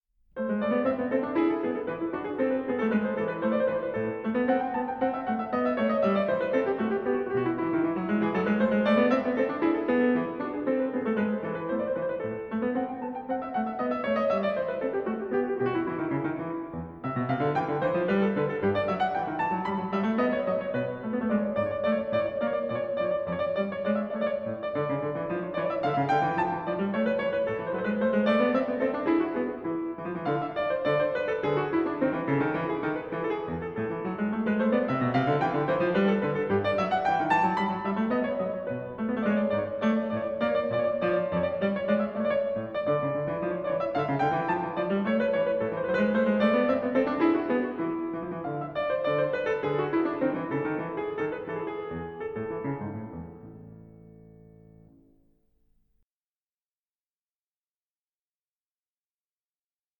Andante 1:38
pianist